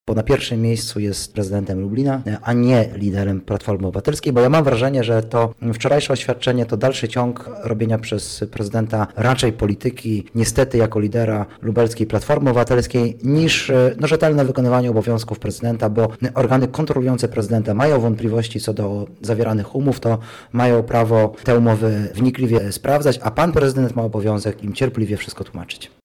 Poseł Soboń komentuje także i teraz: